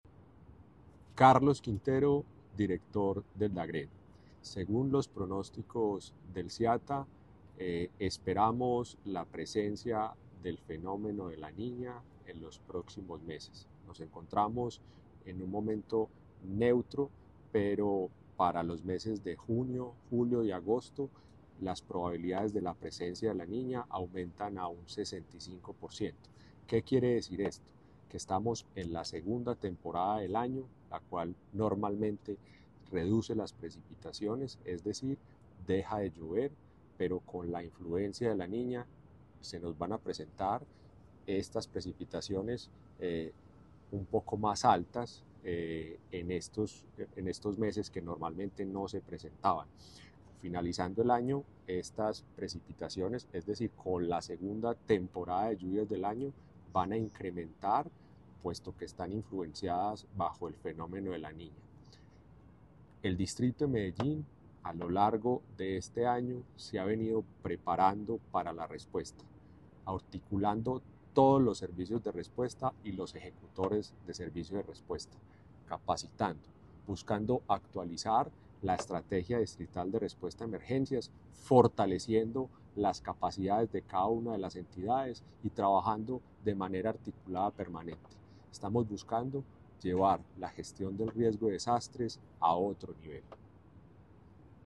Palabras de Carlos Andrés Quintero, director del Dagrd